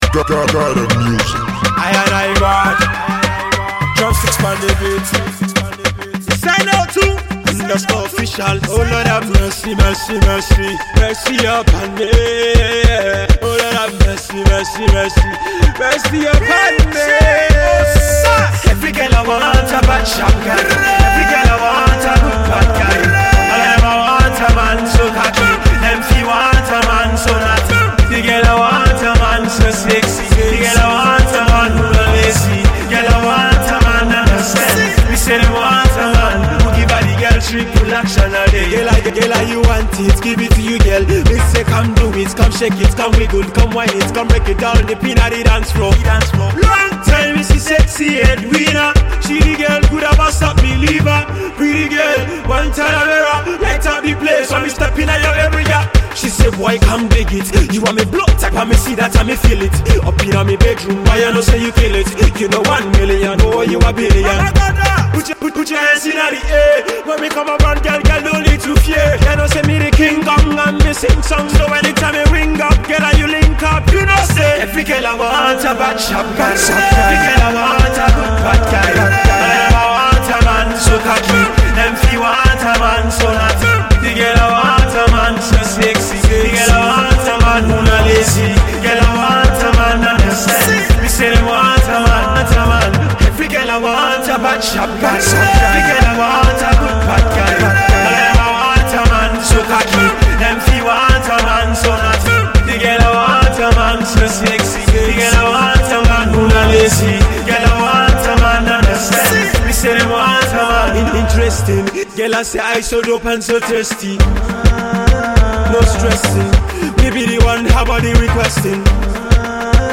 Banger